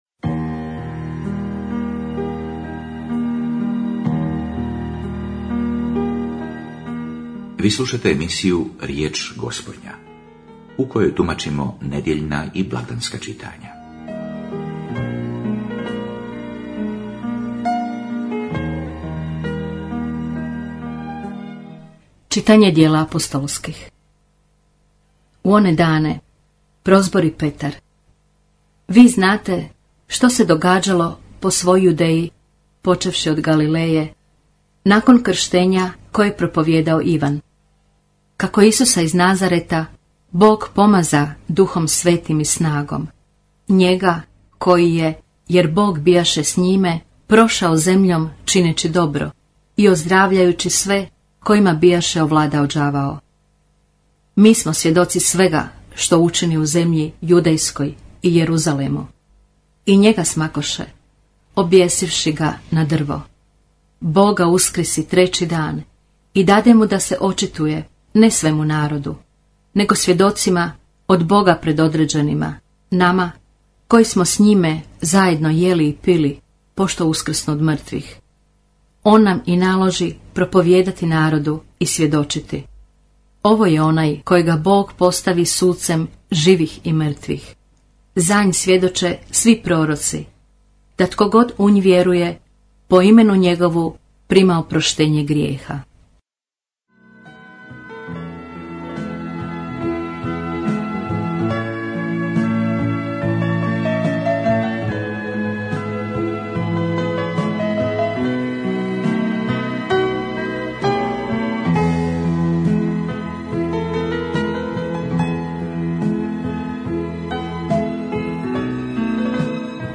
Radio Marija Hrvatska - Riječ Gospodnja - homilija apostolskog nuncija u RH mons. Giorgia Lingue